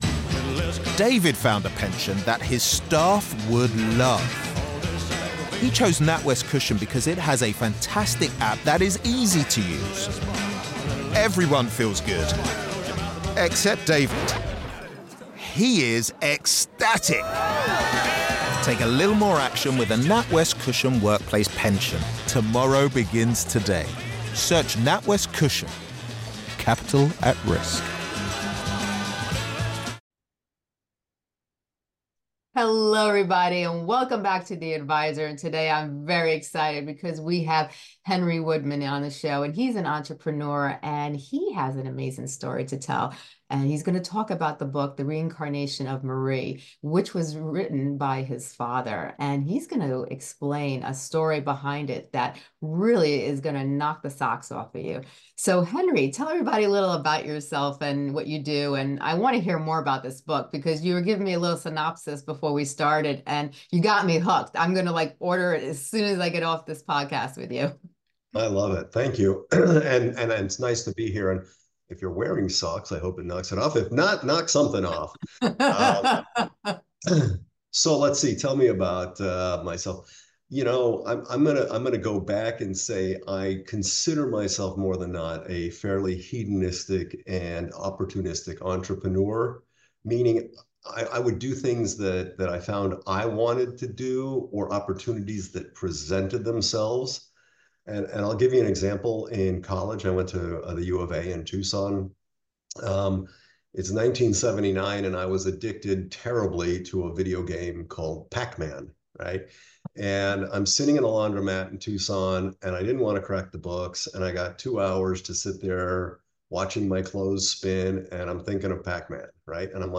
In this conversation, we explore the themes of love, magic, and the extraordinary elements that bring this story to life.